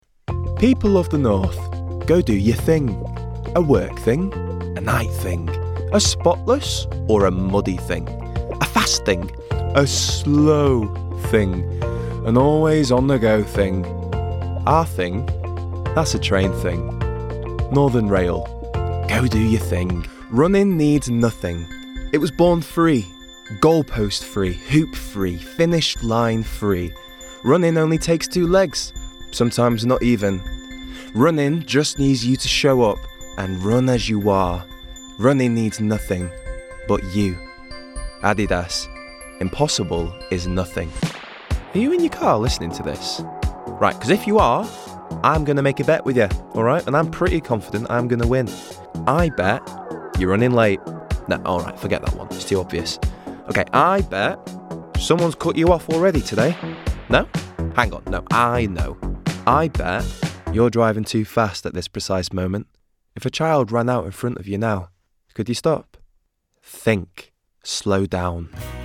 20s – 30s. Male. Manchester.
Commercials